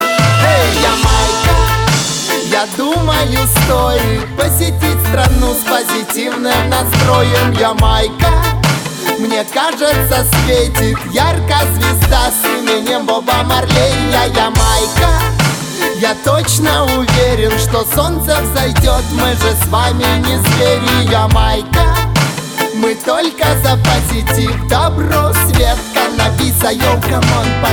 • Качество: 320, Stereo
позитивные
ритмичные
мужской вокал
мелодичные
заводные
dance
спокойные
club
звонкие